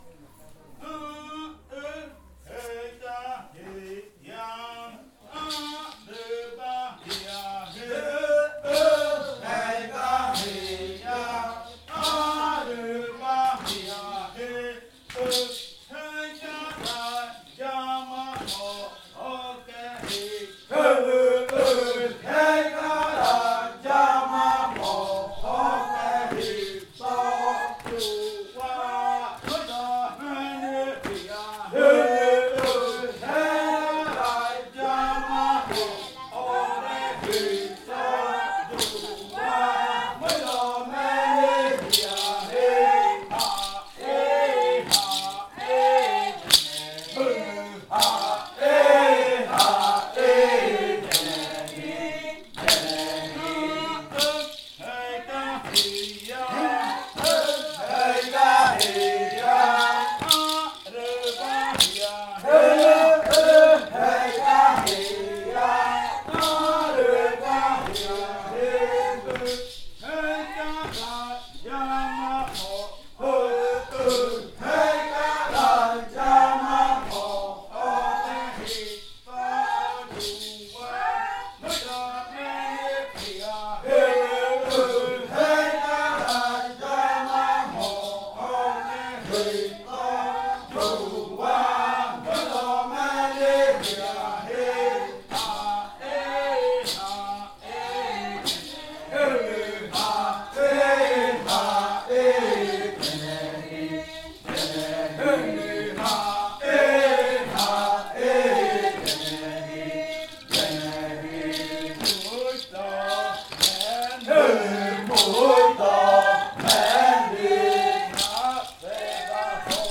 Segundo canto de entrada de la variante jaiokɨ
con el grupo de cantores bailando en Nokaido. Este canto hace parte de la colección de cantos del ritual yuakɨ murui-muina (ritual de frutas) del pueblo murui, llevada a cabo por el Grupo de Danza Kaɨ Komuiya Uai con apoyo de la UNAL, sede Amazonia.